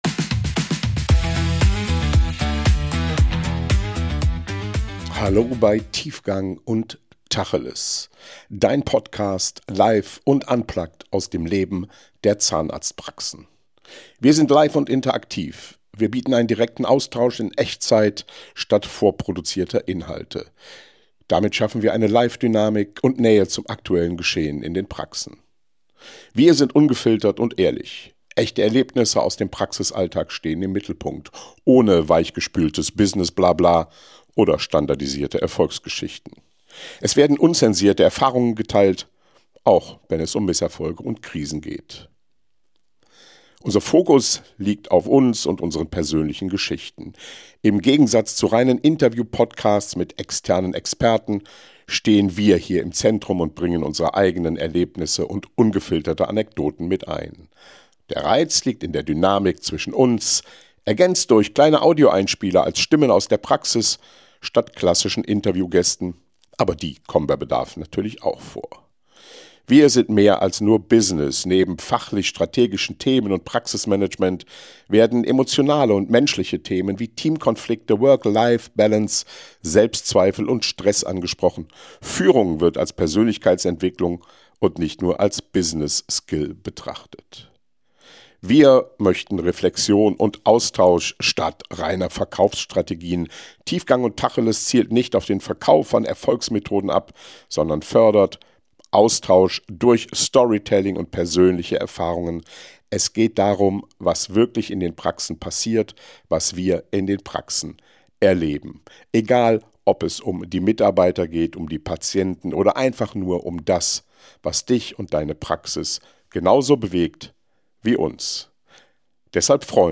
Tiefgang+Tacheles: Live + unplugged aus dem Leben der Zahnarztpraxis